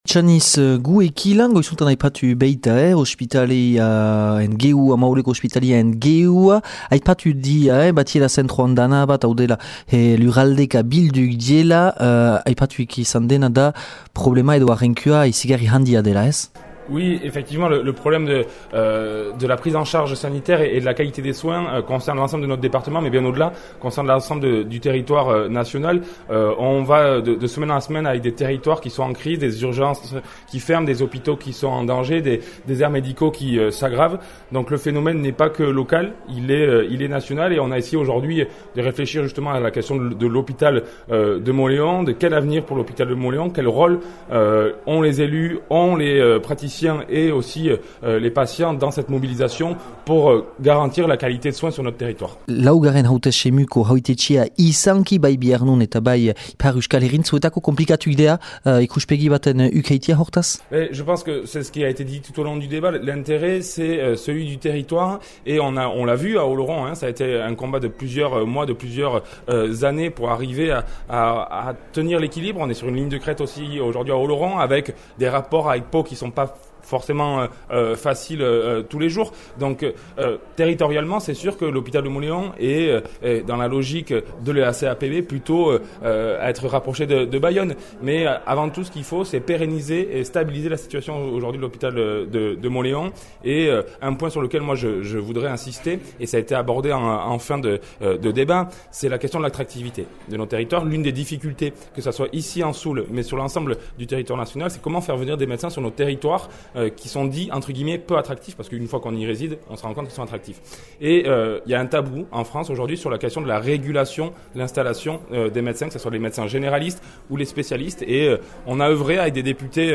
Üngürgiro hortan dü antolatü EH Bai alderdiak Ospitalearen geroaz mahain üngürü bat neskenegünean.
Inaki Etxaniz Depütatüa :